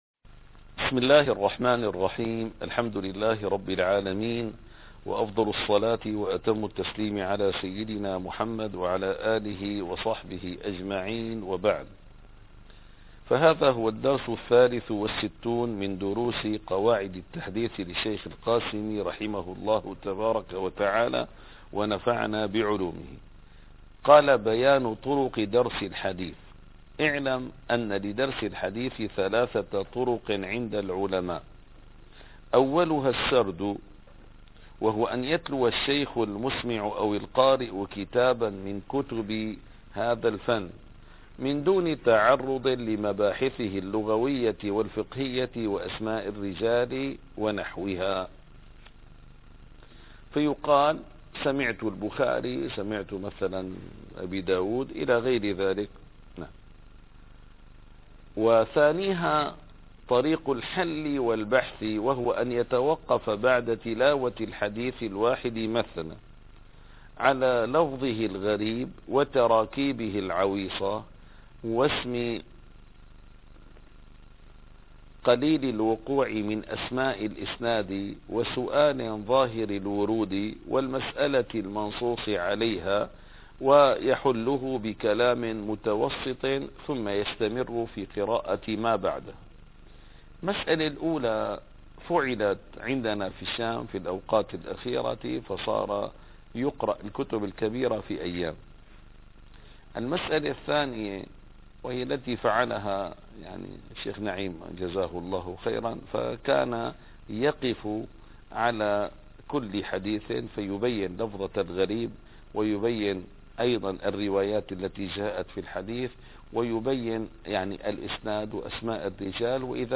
- الدروس العلمية - قواعد التحديث من فنون مصطلح الحديث - 63- بيان طرق درس الحديث ص243